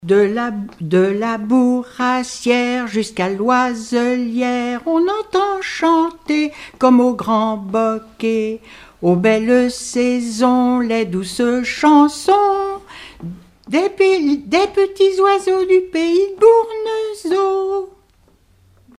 Collectif-veillée (2ème prise de son)
Pièce musicale inédite